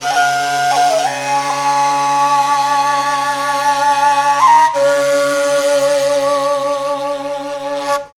TIBETDRON1-R.wav